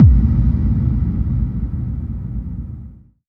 VEC3 FX Reverbkicks 17.wav